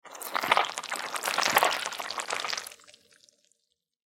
Звуки вампира
Вампир пожирает человеческую плоть и ковыряется в теле